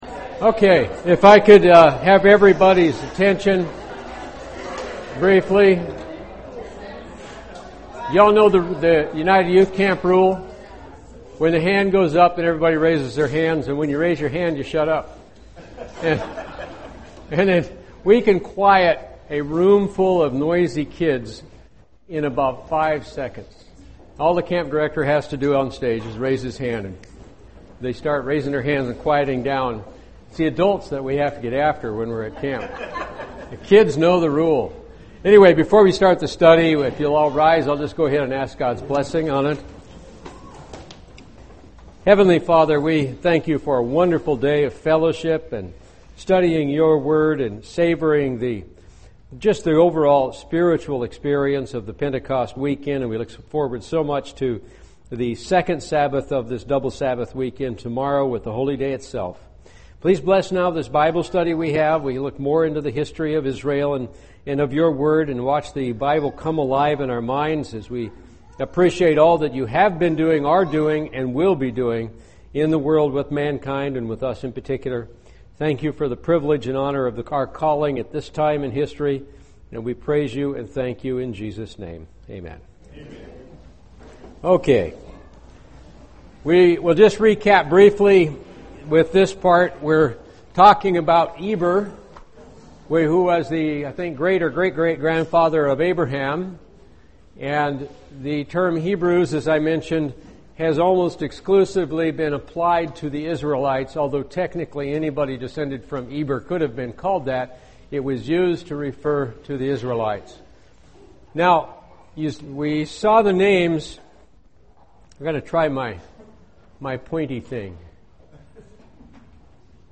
A Bible study tracking the descendants of the twelve tribes of Israel.
UCG Sermon Studying the bible?